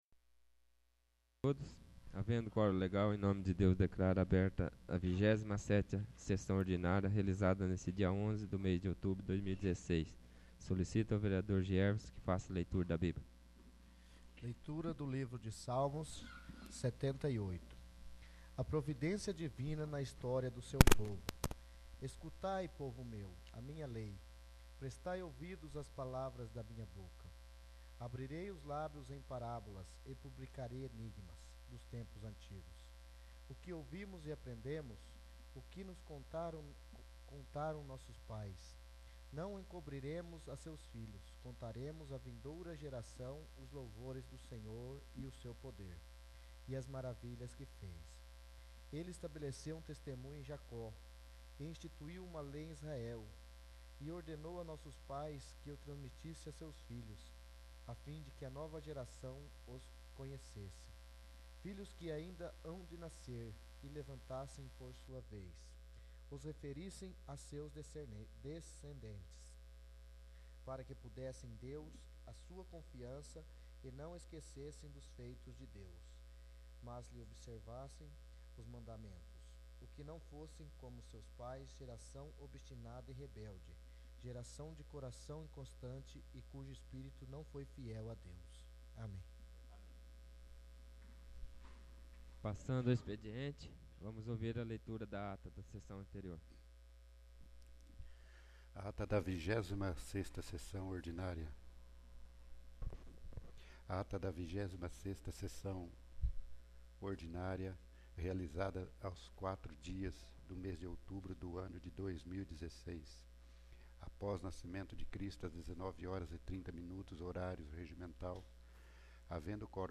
27º. Sessão Ordinária 11/10/2016
27º. Sessão Ordinária